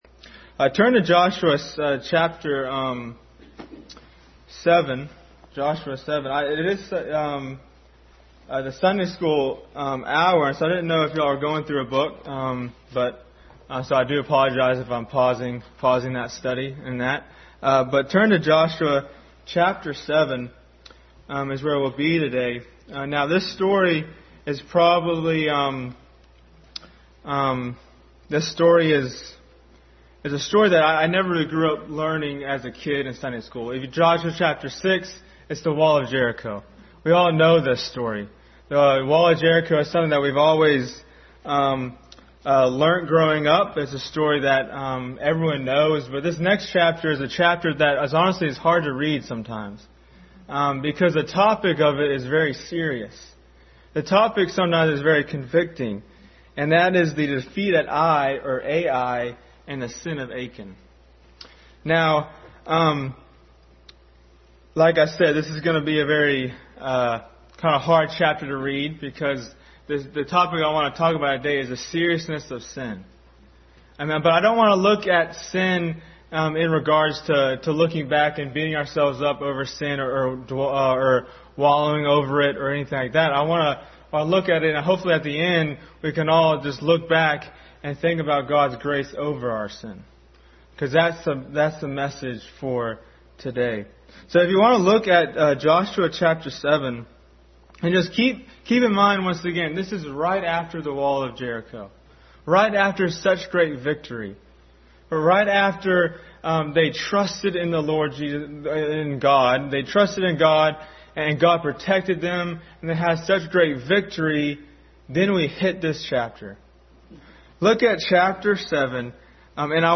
Adult Sunday School Class.